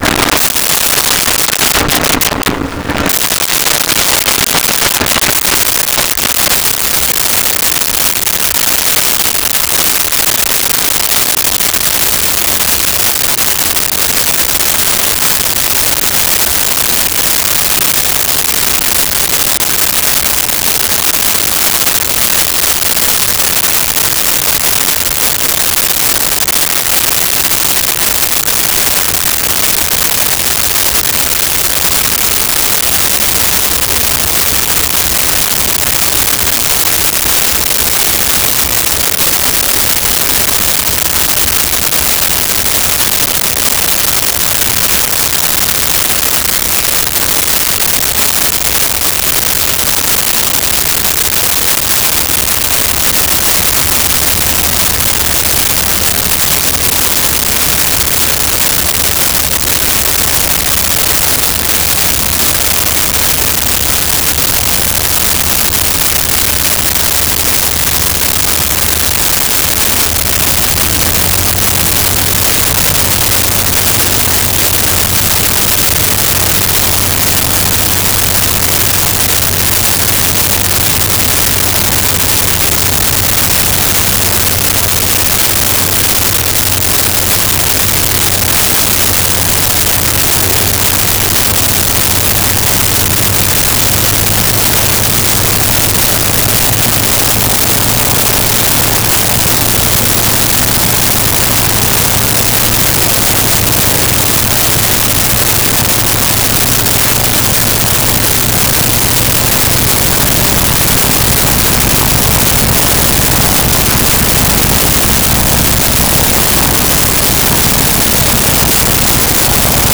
Plane Brossard Start Idle Off
Plane Brossard Start Idle Off.wav